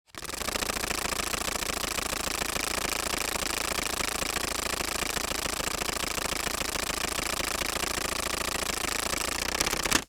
Звуки долларов
Звук пересчета долларов аппаратом для подсчета купюр счетчик банкнот